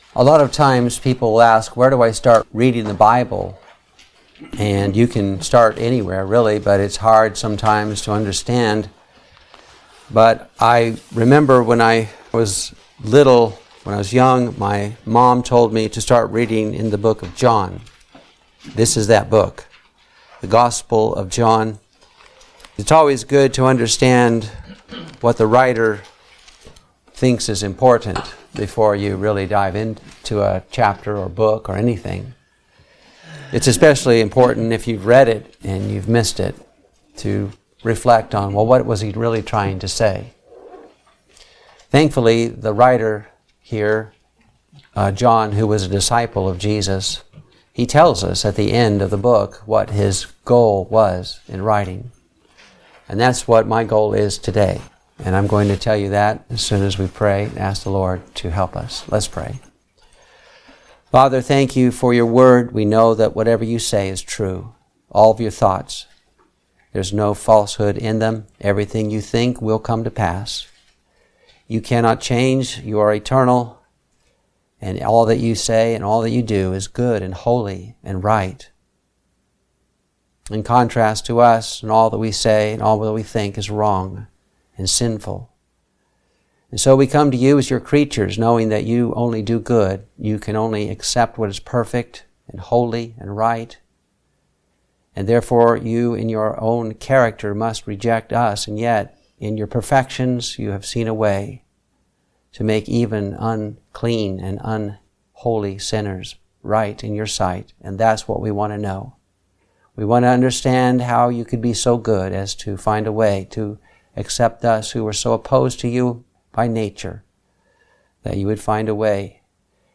Christ the Bread of Life | SermonAudio Broadcaster is Live View the Live Stream Share this sermon Disabled by adblocker Copy URL Copied!